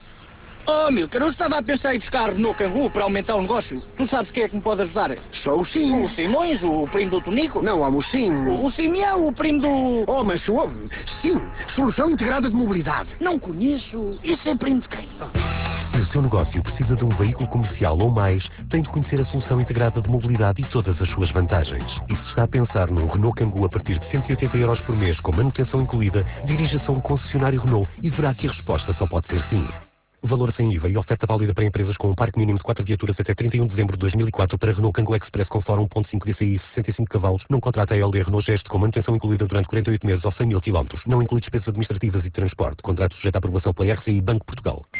clique para ouvir o spot) presente nas rádios RR, RFM, TSF, RC obteve nos 4 primeiros dias um investimento de 40 359 euros para 97 inserções.